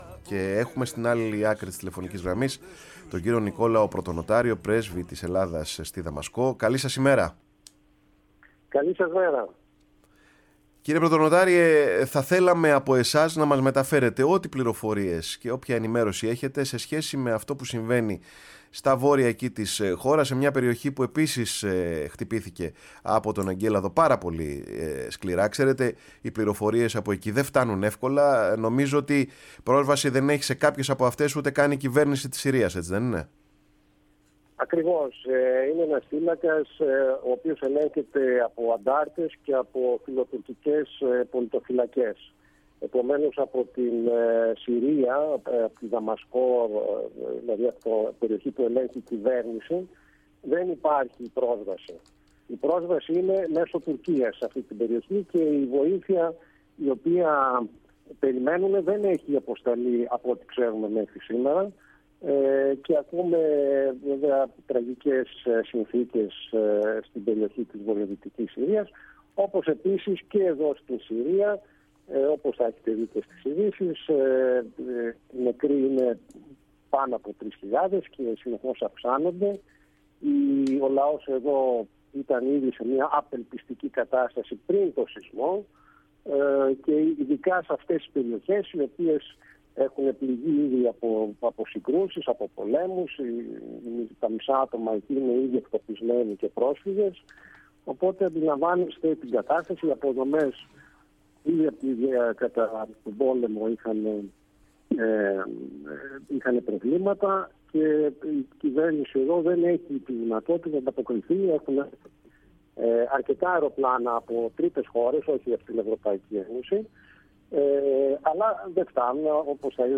Σε απευθείας τηλεφωνική επικοινωνία με τη Δαμασκό, στο “Πάρε τον Χρόνο σου” μίλησε ο πρέσβης της Ελλάδας στη Συρία, Νικόλαος Πρωτονοτάριος, για την κατάσταση στην περιοχή στα βόρεια, όπου χτύπησε ο μεγάλος σεισμός. Ο Έλληνας διπλωμάτης, περιέγραψε με μελανά χρώματα την κατάσταση στη χώρα μετά τον πόλεμο, συγκρίνοντάς τη με τη μετεμφυλιακή Ελλάδα, με σωρευμένα πάνω της τα προβλήματα μιας τεράστιας οικονομικής κρίσης και την αδυναμία ανάκαμψης και ανοικοδόμησης, εξαιτίας και των κυρώσεων της Δύσης.
Η ΦΩΝΗ ΤΗΣ ΕΛΛΑΔΑΣ Παρε τον Χρονο σου ΕΝΗΜΕΡΩΣΗ Ενημέρωση ΟΜΟΓΕΝΕΙΑ ΣΥΝΕΝΤΕΥΞΕΙΣ Συνεντεύξεις Νικολαος Πρωτονοταριος Πρεσβεια της Ελλαδας στη Συρια Σεισμος Συρια